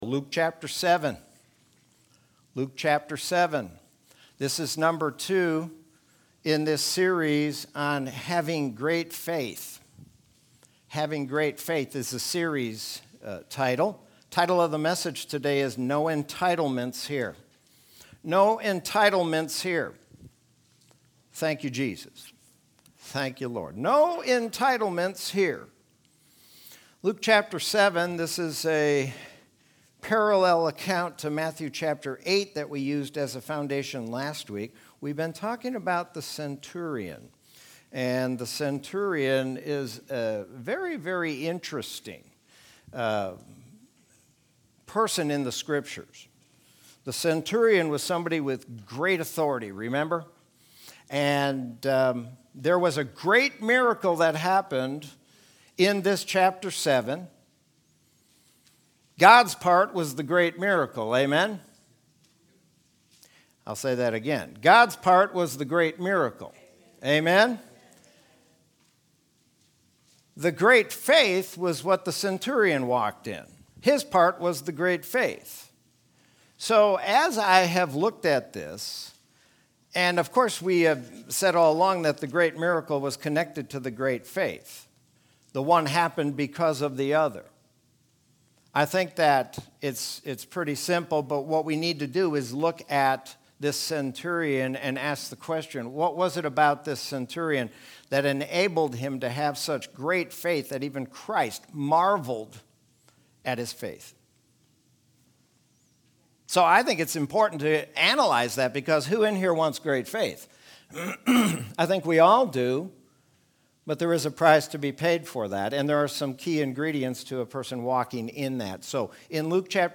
Sermon from Sunday, May 30th, 2021.